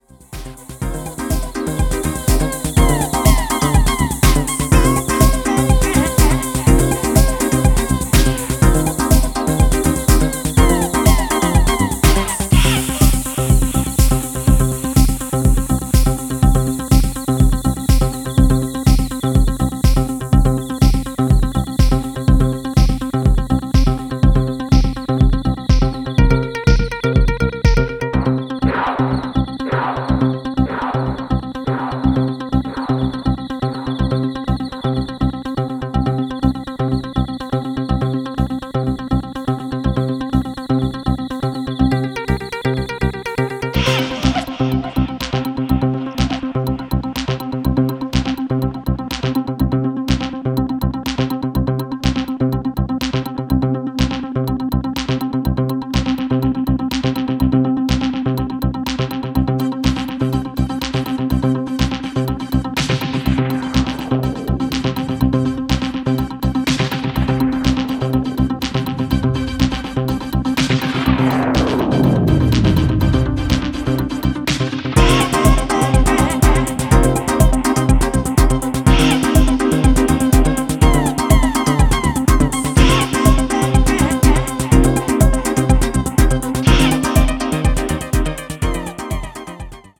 全体的に低重心のグルーヴ感もナイスで、Nu Disco方面の音好きもぜひチェックしてみてください。